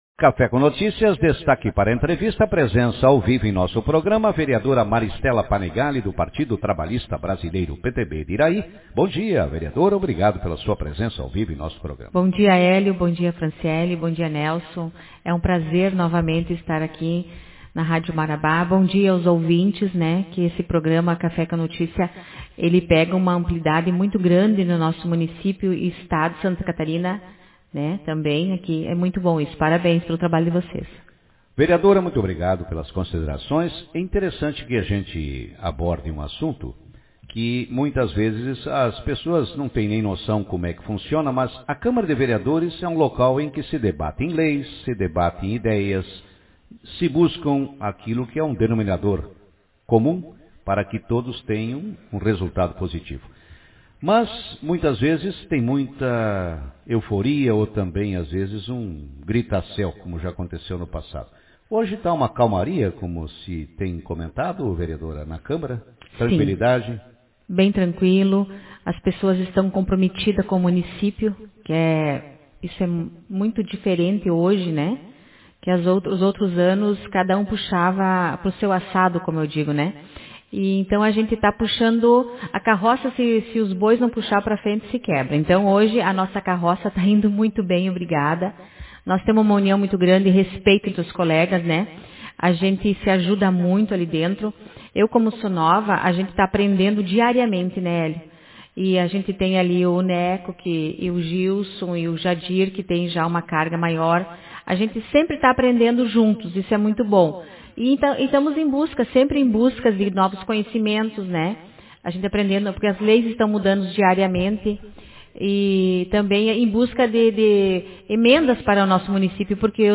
Vereadora Maristela Panegalli, PTB, fala sobre a atividade parlamentar junto ao legislativo iraiense Autor: Rádio Marabá 04/11/2021 Manchete Na manhã de hoje no programa Café com Notícias, a vereadora iraiense, Maristela Panegalli – PTB, falou sobre a conquista de recursos para o município através de emendas parlamentares, bem como do clima de respeito e de harmonia que existe atualmente na Câmara Municipal de Vereadores. Vamos acompanhar a matéria com o repórter